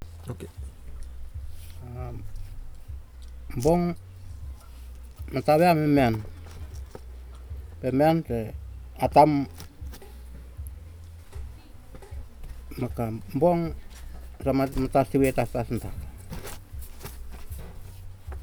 dc.description.regionEmiotungan, Ambrym, Vanuatu
dc.formatdigital wav file recorded at 44.1 kHz/16 bit on Marantz PMD 620 recorder